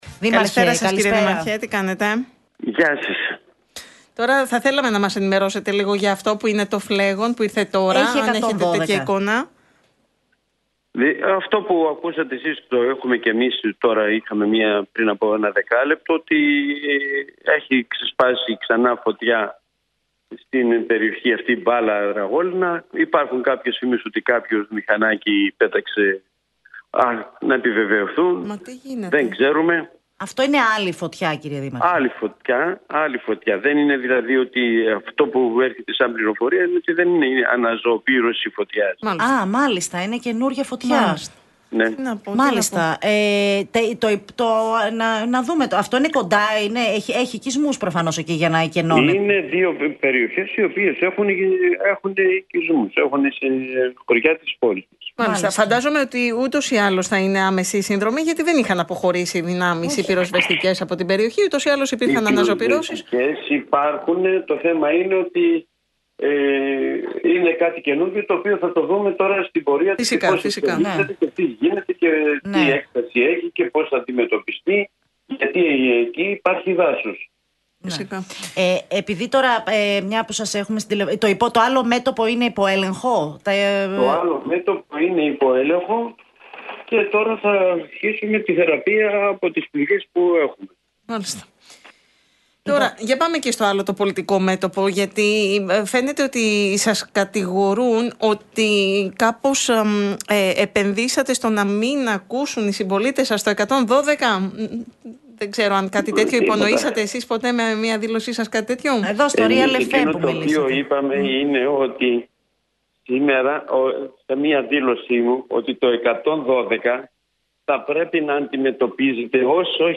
Ο Κώστας Πελετίδης, μιλώντας στον RealFm 97,8, άσκησε σκληρή κριτική στην Πολιτεία για τον τρόπο αντιμετώπισης των πυρκαγιών στην Αχαΐα, υποστηρίζοντας ότι η εκκένωση περιοχών, μέσω του 112, «είναι ένας όρος ο οποίος βγήκε στην ζωή για να αποφύγουμε τις ευθύνες».